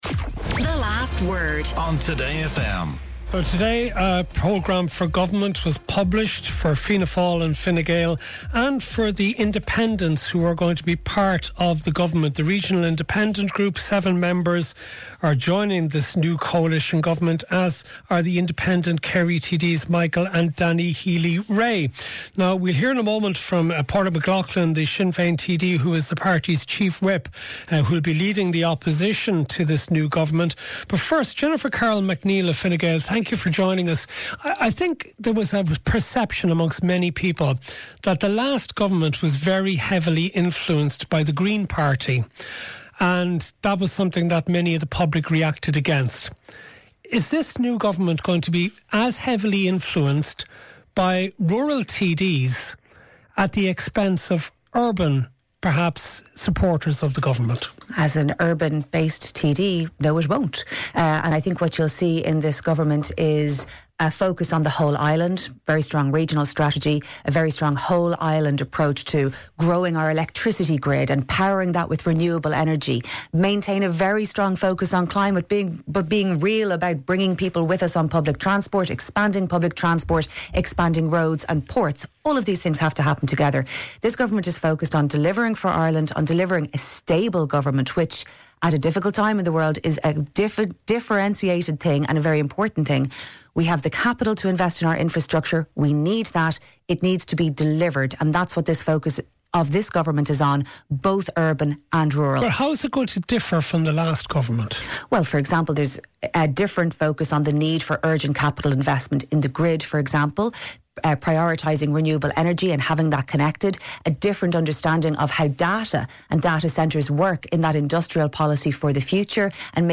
On Ireland's most cutting edge current affairs show, Matt and his guests provide a running stream of intelligent opinions and heated debates on the issues that matter most to Irish listeners.